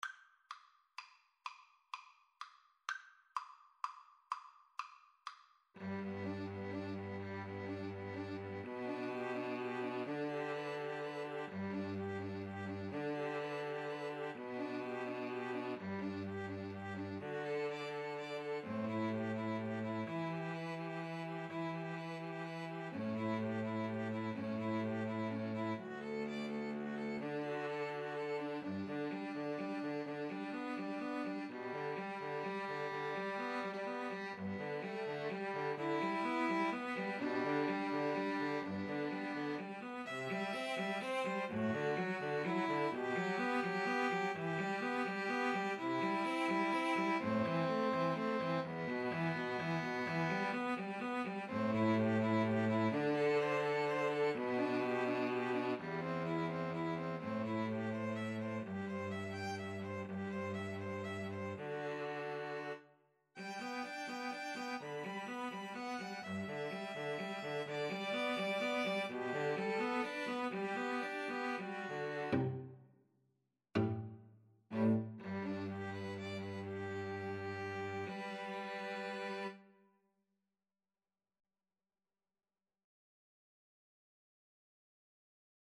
Violin 1Violin 2Cello
G major (Sounding Pitch) (View more G major Music for 2-Violins-Cello )
6/8 (View more 6/8 Music)
Andante ingueno .=42
Classical (View more Classical 2-Violins-Cello Music)
puccini_beloved_father_2VNVC_kar1.mp3